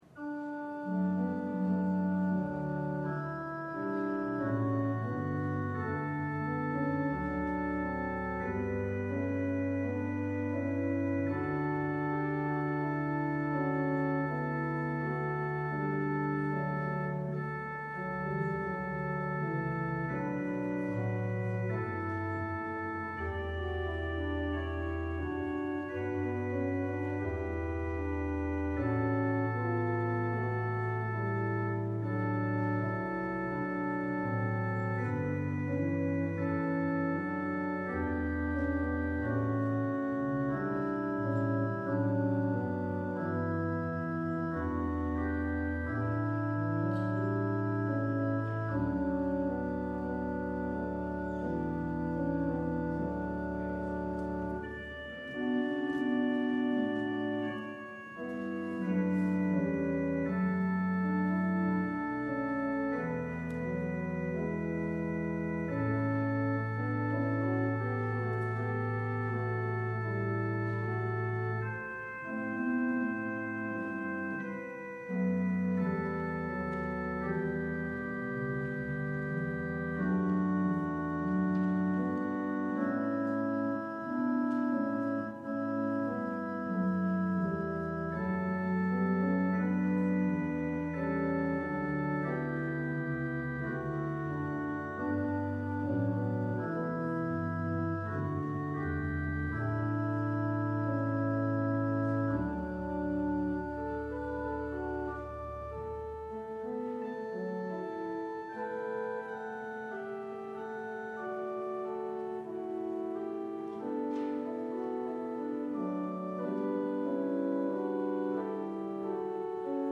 LIVE Evening Worship Service - The Life of the Spirit